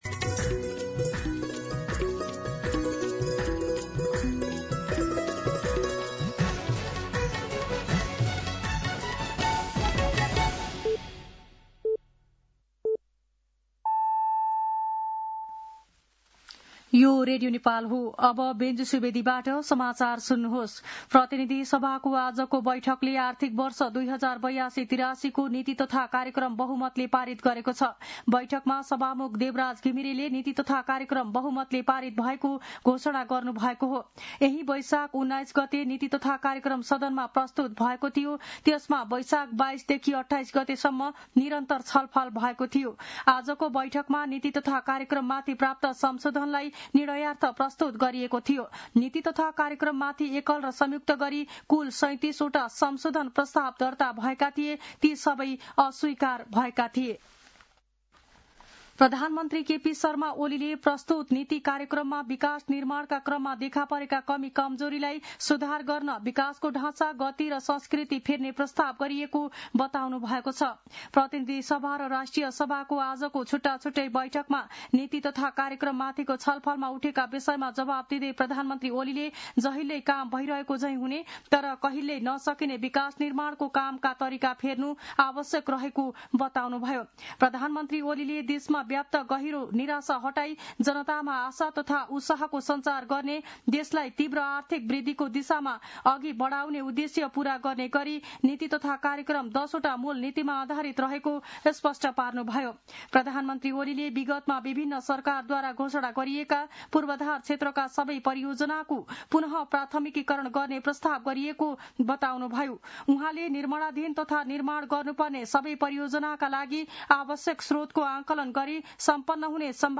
दिउँसो ४ बजेको नेपाली समाचार : २८ वैशाख , २०८२
4-pm-Nepali-News-2.mp3